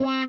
WAH SOLO 1.wav